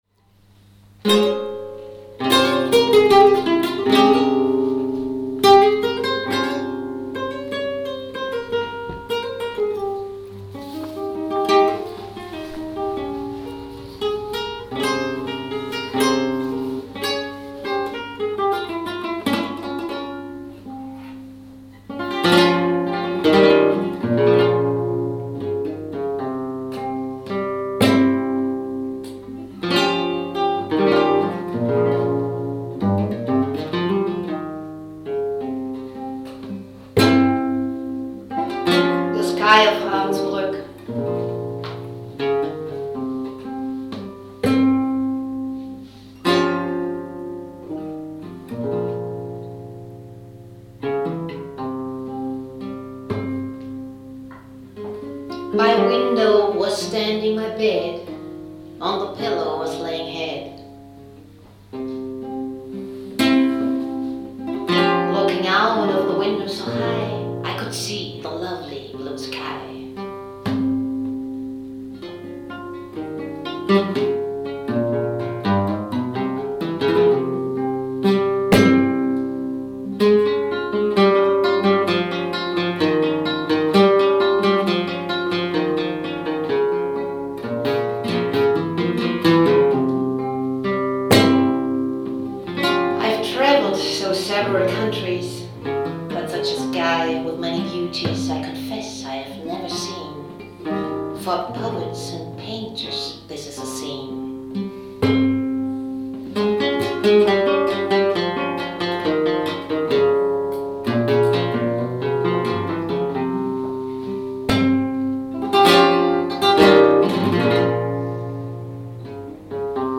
Musik in der Tradition des Flamenco
Das Besondere dieser szenischen Lesung ist die von uns geschaffene Verbindung mit musikalischen Improvisationen, die in der Tradition des Flamenco stehen.